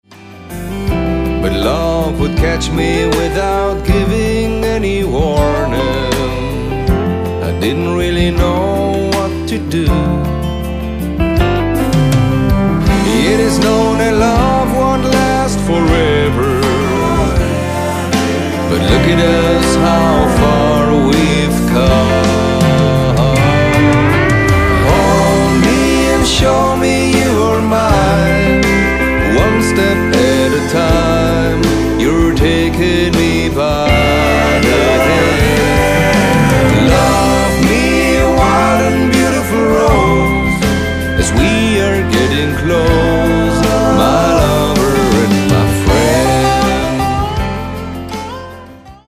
Stil / Art: Country, Bluegrass, Country-Rock
Aufgenommen: Hilltop Studios Nashville, U.S.A.